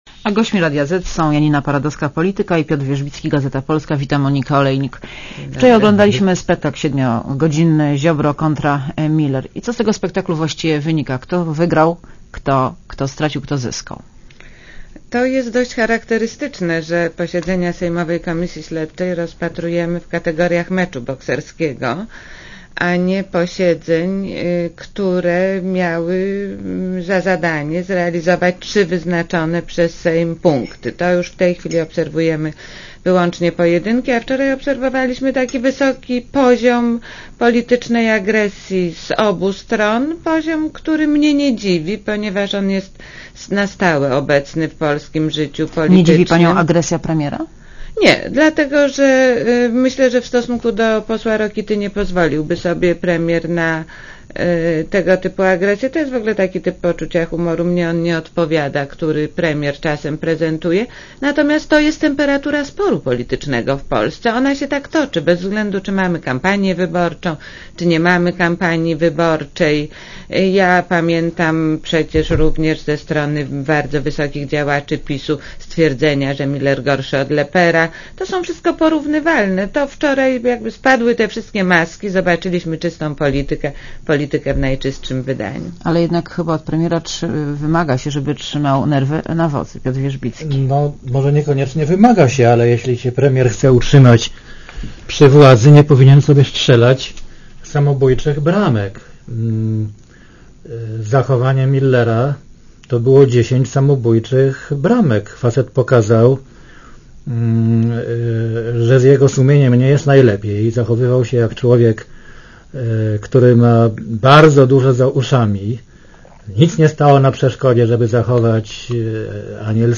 Piotr Wierzbicki i Janina Paradowska (PAP) Źródło: Piotr Wierzbicki i Janina Paradowska (PAP) (RadioZet) Źródło: (RadioZet) Posłuchaj wywiadu (2,7 MB) Wczoraj oglądaliśmy siedmiogodzinny spektakl - Ziobro kontra Miller - i co z tego spektaklu właściwie wynika: kto wygrał, kto stracił, kto zyskał?